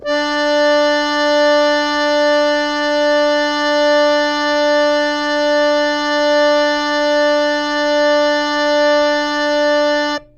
harmonium
D4.wav